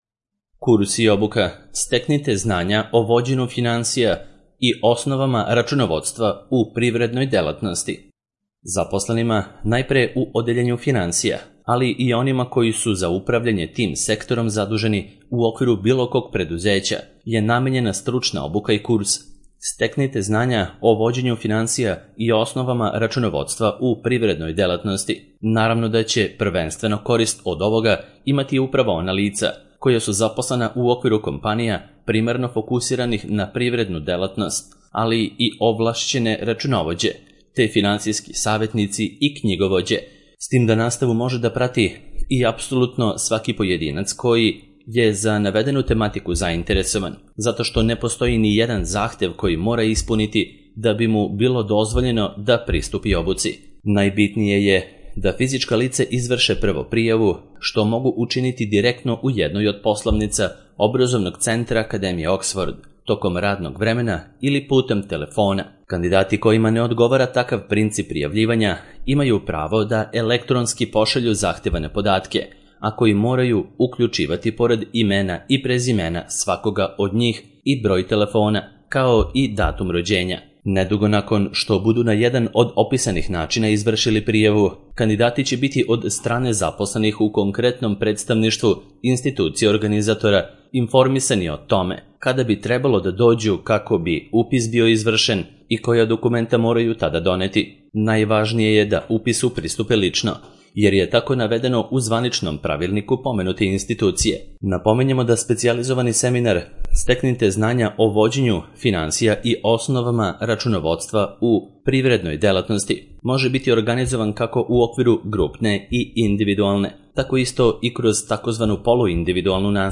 Steknite znanja o vođenju finansija i osnovama računovodstva u privrednoj delatnosti - Audio verzija